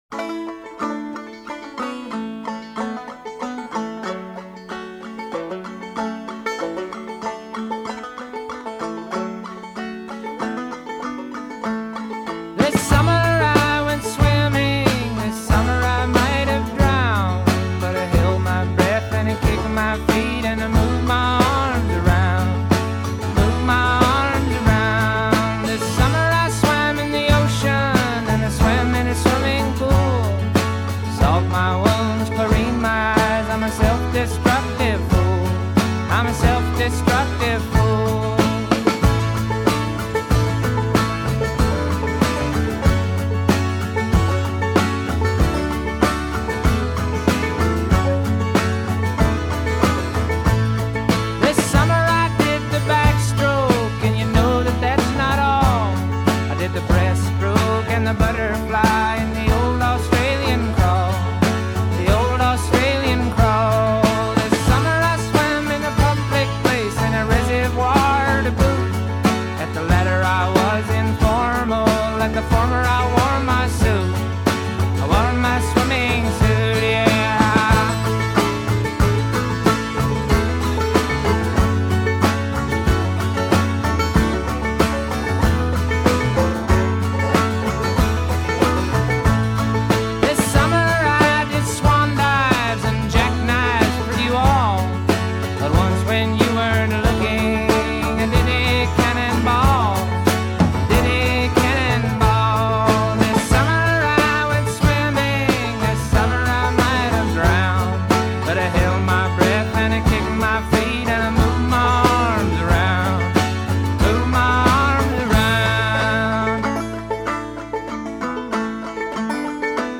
Recorded in Nashville
banjo